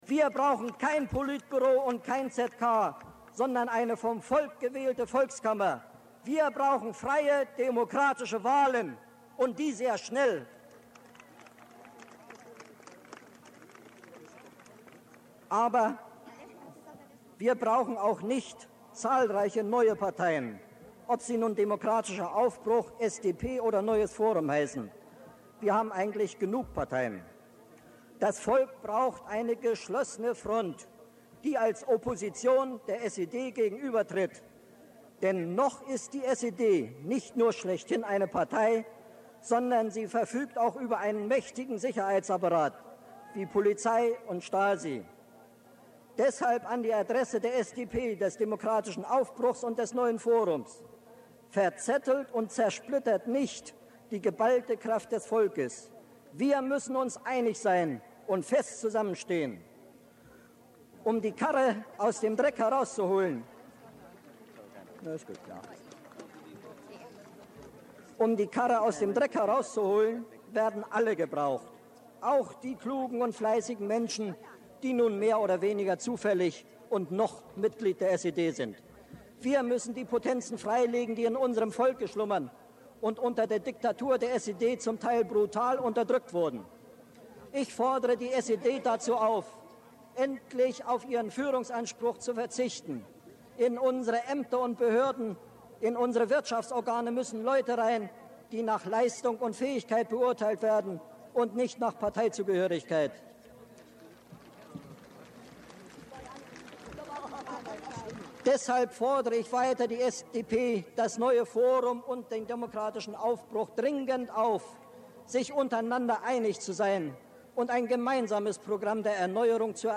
Demo auf dem August-Bebel-Platz am 21. November 1989